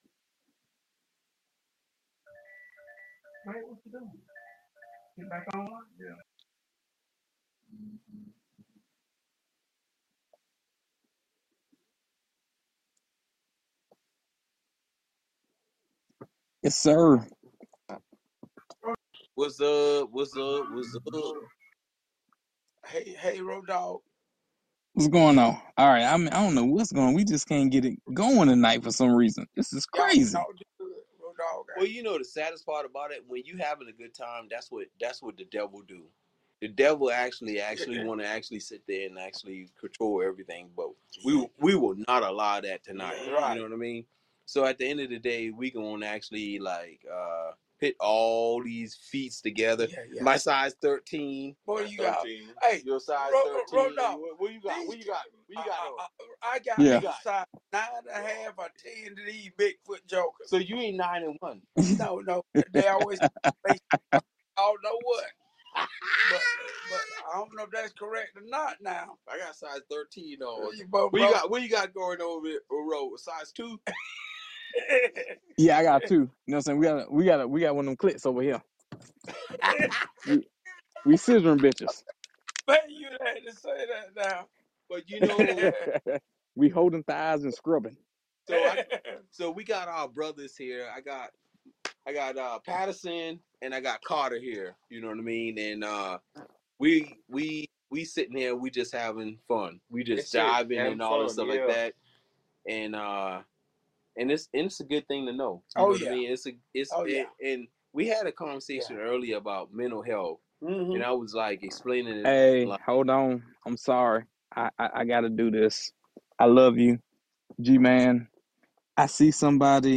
Episode 30: 5 guys and a conversation
Conversation with Run N Tell That Podcast